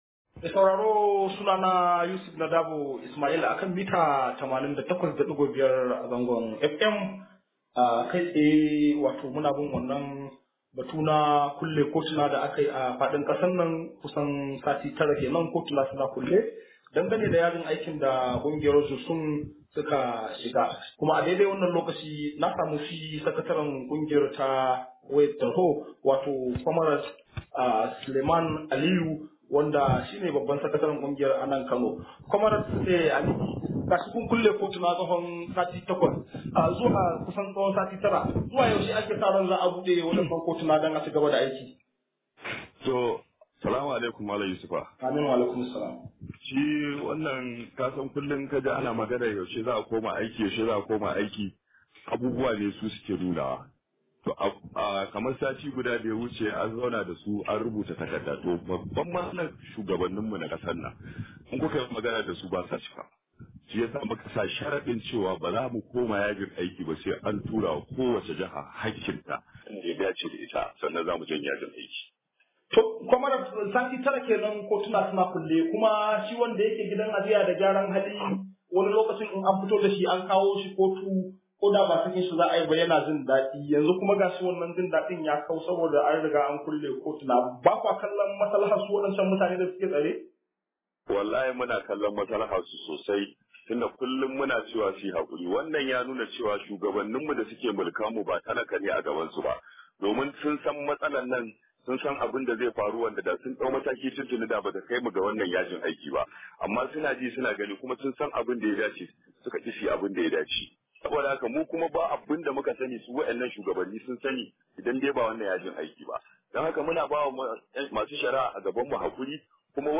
Wakilin namu nada cikakken rahoton.